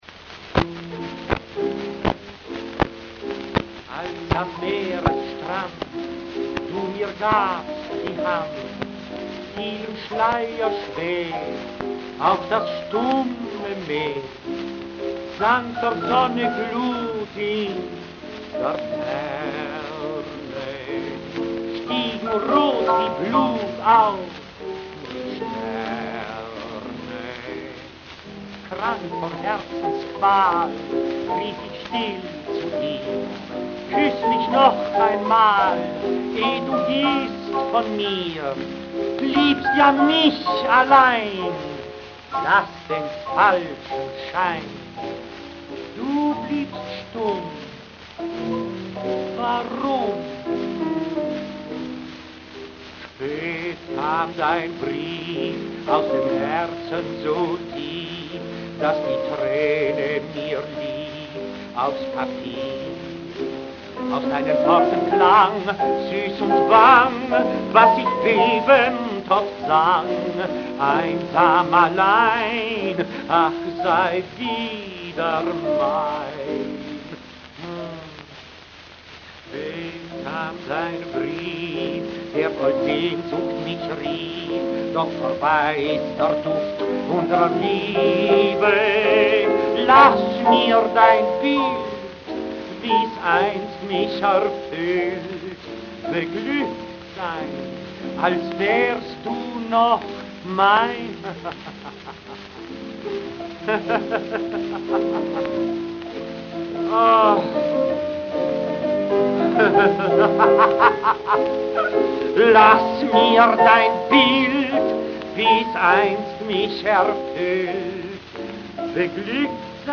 Flügel
Chansons
Boston